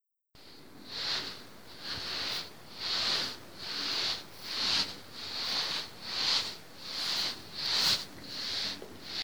Barriendo
Me gusta Descripción Grabación sonora en la que se escucha el sonido de alguien barriendo con un cepillo o escoba.
Sonidos: Acciones humanas Sonidos: Hogar